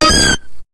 Cri de Toudoudou dans Pokémon HOME.